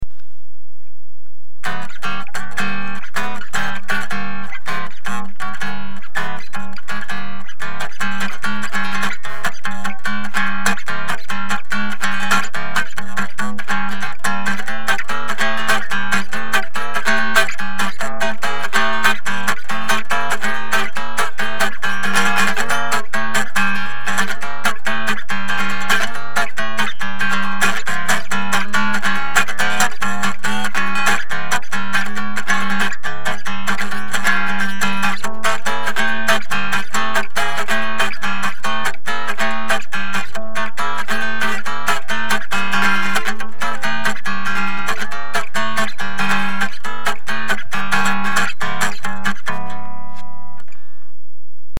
gratte de voyage
un manche, fond éclisse en érable ( ondé pour le fond éclisse ) et une table en épicéa.
pas mal de défaut au niveau de la fileterie mais un son acceptable et finalement assez puissant au regard de la taille de la caisse. je l’ai toujours et elle sonne toujours bien.